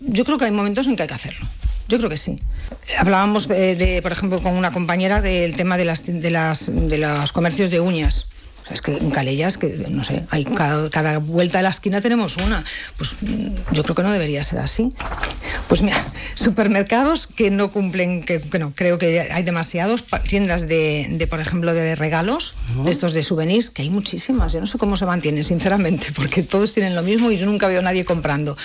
La regidora del Partit Popular de Calella, Celine Coronil, ha fet balanç de l’actualitat local en una entrevista al matinal de RCT on ha abordat temes clau com el turisme, el comerç local i neteja.